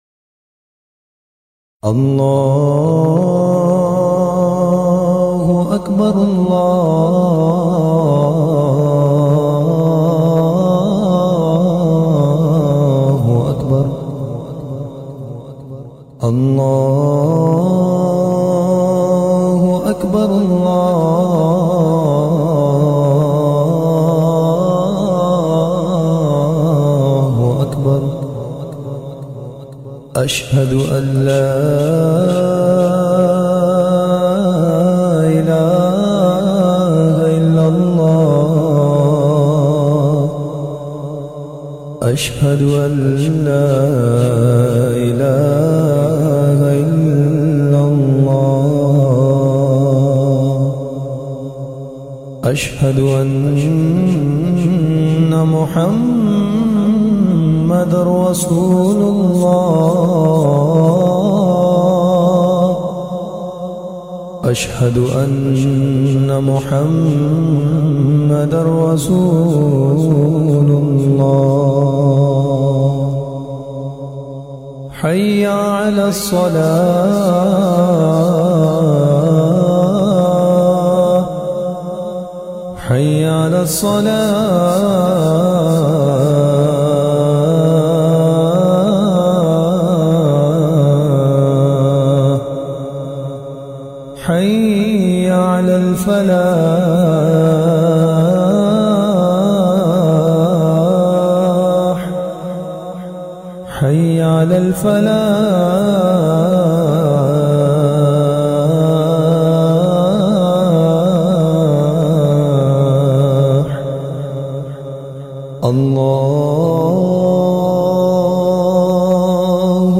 Adhan in a beautiful voice
پر سوز لہجے میں پیاری اذان سنیے ZiaeTaiba Audio میڈیا کی معلومات نام پر سوز لہجے میں پیاری اذان سنیے موضوع متفرق آواز دیگر زبان عربی کل نتائج 2241 قسم آڈیو ڈاؤن لوڈ MP 3 ڈاؤن لوڈ MP 4 متعلقہ تجویزوآراء
adhan-in-a-beautiful-voice.mp3